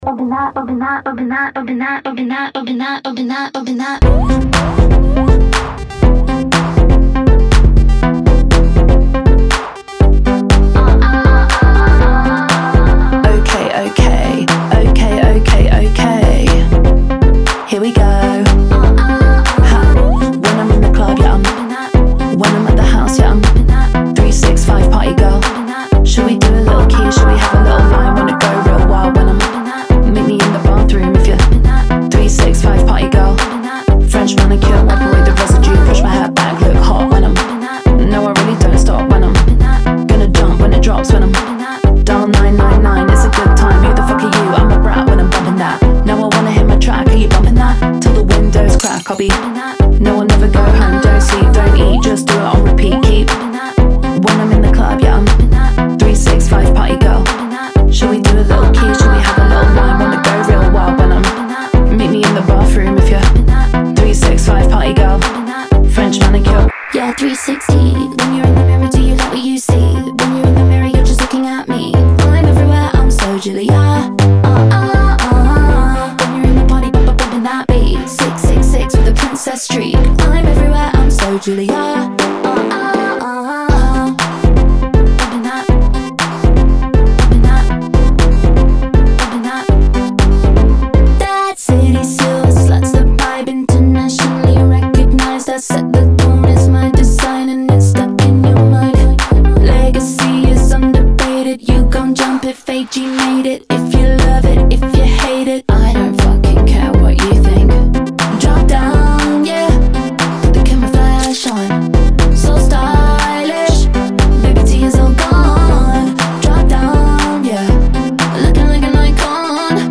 Sound: Stereo